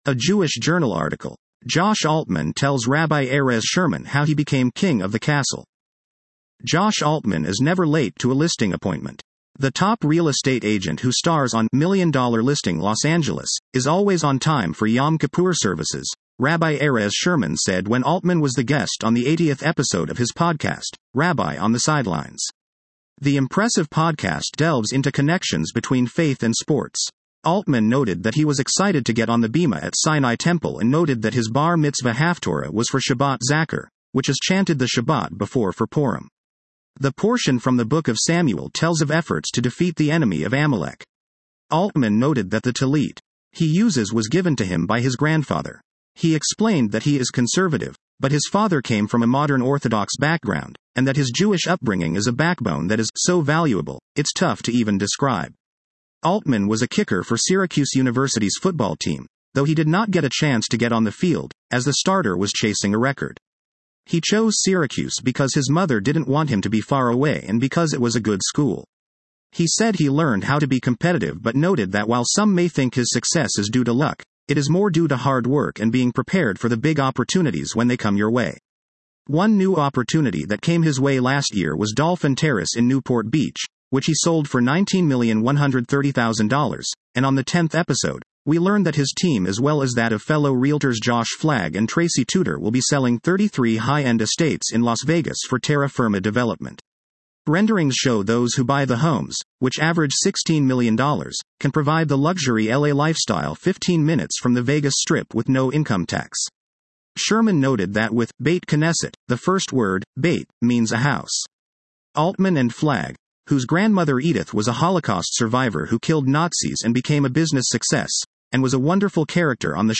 interviews Josh Altman on “Rabbi On The Sidelines.”